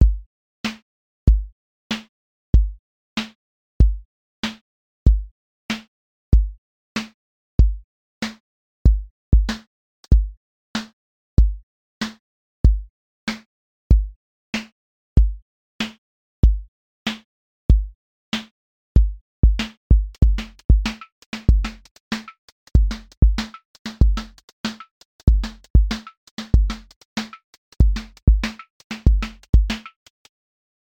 QA Listening Test boom-bap Template: boom_bap_drums_a
• voice_kick_808
• voice_snare_boom_bap
• voice_hat_rimshot
• tone_warm_body
• voice_sub_pulse
• motion_drift_slow
• mix_dry_punch